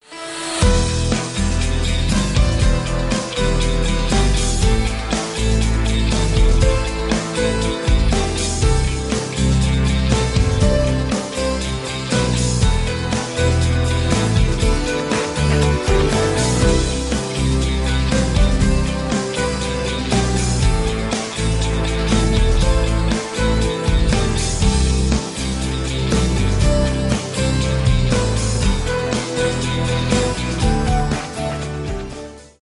без слов
гитара , инструментальные